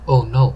snd_player_pchuuun.wav